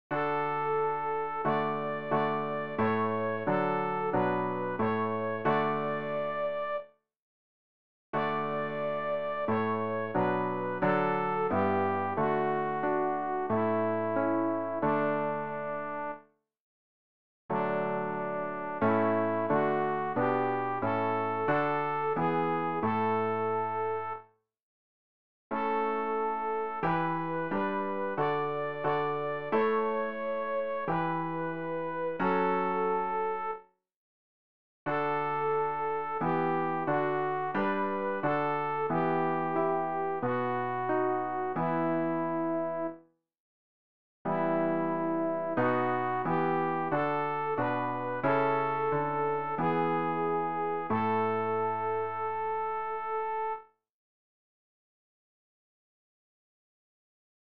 sopran-rg-082-wo-gott-der-herr-das-haus-nicht-baut.mp3